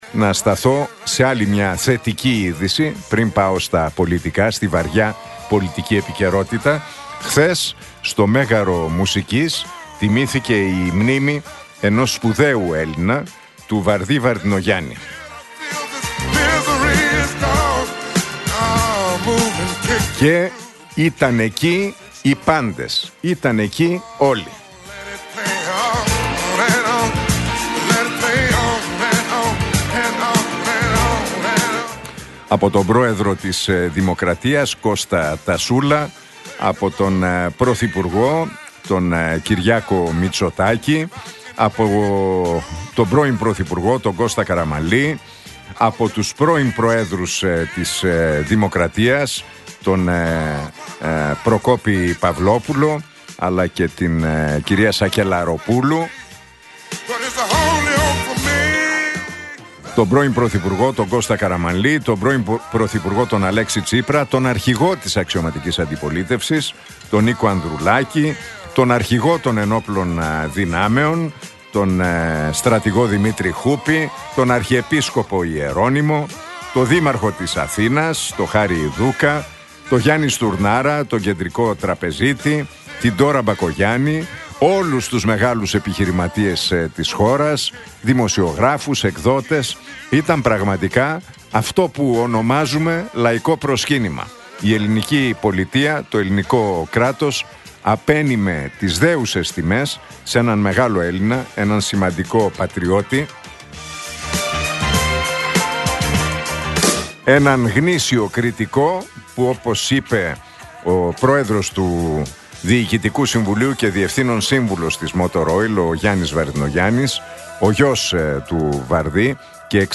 Στη συγκινητική εκδήλωση μνήμης και τιμής για τον Βαρδή Ι. Βαρδινογιάννη, που πραγματοποιήθηκε το βράδυ της Τρίτης στο Μέγαρο Μουσικής Αθηνών αναφέρθηκε σήμερα ο Νίκος Χατζηνικολάου από την ραδιοφωνική εκπομπή του στον Realfm 97,8.